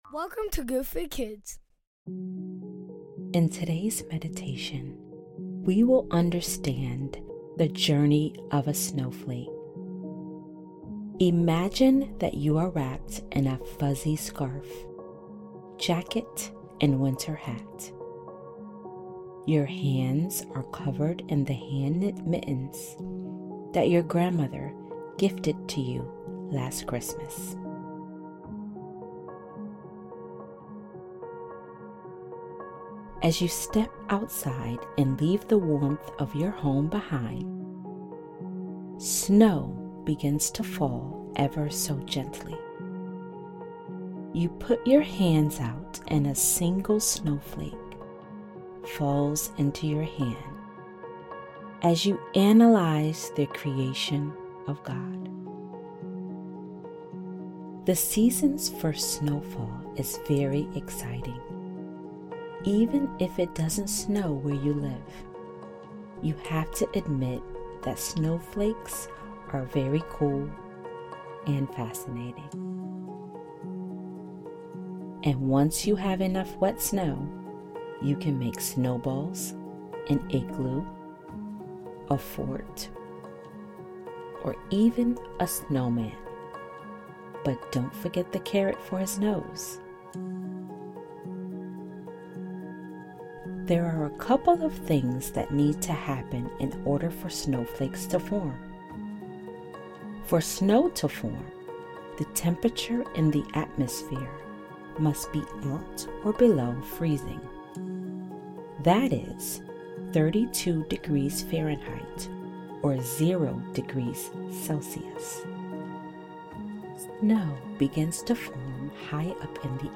This meditation encourages you to take things slow just as a single snowflake reaches the ground.